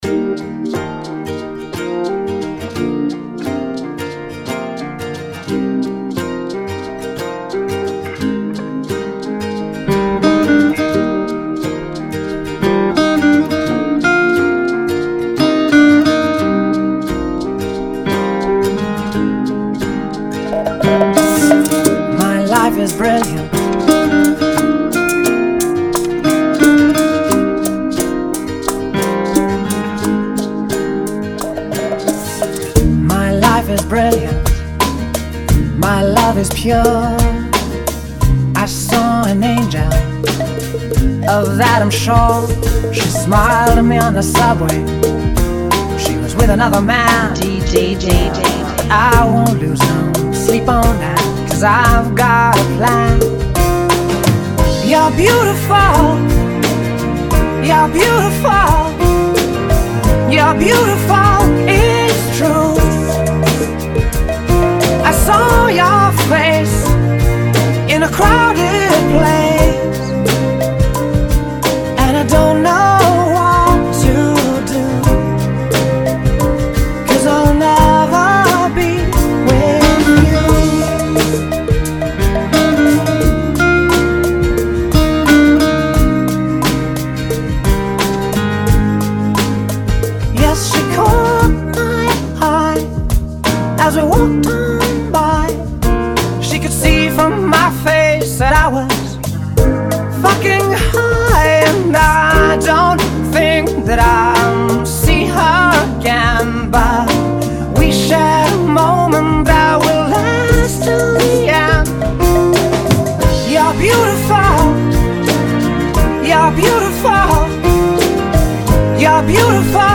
87 BPM
Genre: Bachata Remix